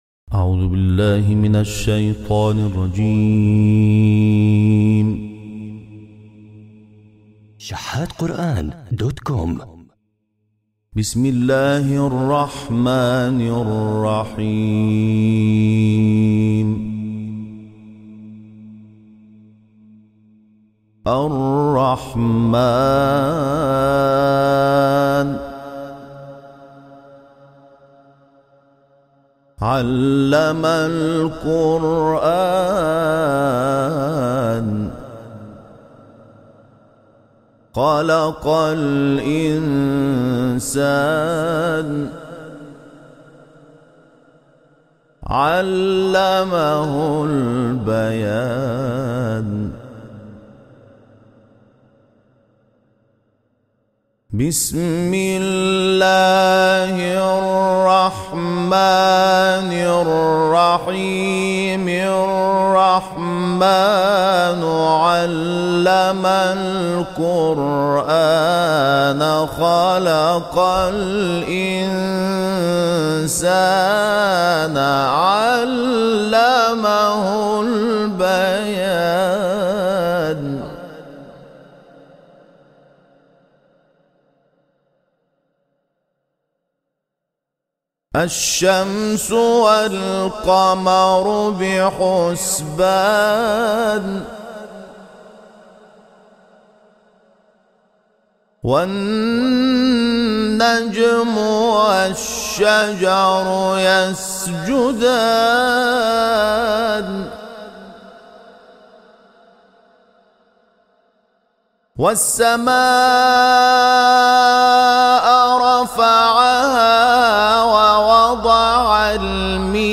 تلاوت‌های استودیویی از «محمود شحات»
گروه شبکه اجتماعی: تلاوت آیاتی از سوره‌های مبارکه الرحمن، شوری و ضحی را که در استودیو توسط محمود شحات انور ضبط شده است، مشاهده می‌کنید.
به گزارش خبرگزاری بین المللی قرآن(ایکنا)، محمود شحات انور، قاری ممتاز مصری، طی سفری که به آفریقای جنوبی داشت، تلاوت‌های استودیویی را در این کشور ضبط کرده است که در کانال تلگرامی این قاری منتشر شد.